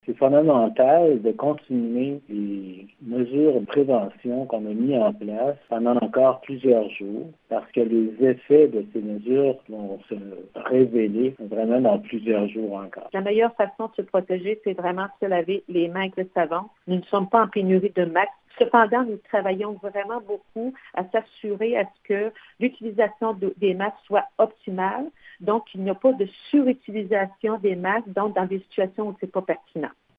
Lors du point de presse, les intervenants du CISSS de la Gaspésie ont confirmé qu’il n’y a toujours aucun cas de coronavirus  dans la région.